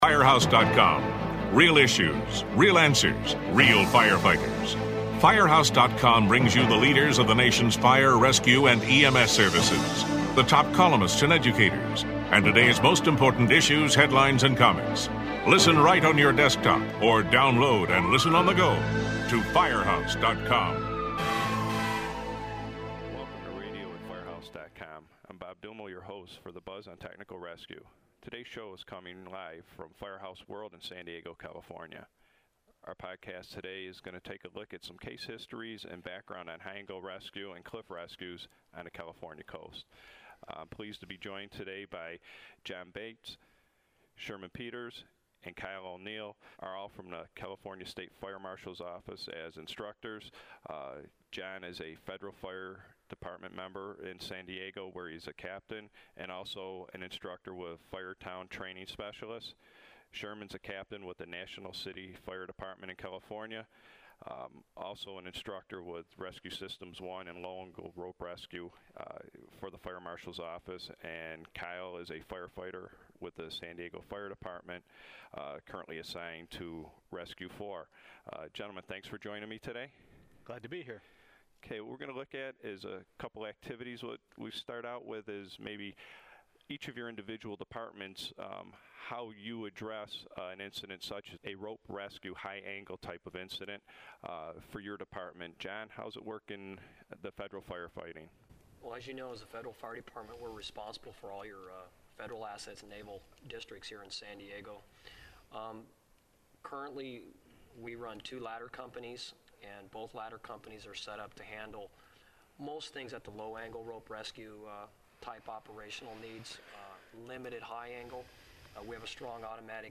This podcast was recorded at Firehouse World in San Diego.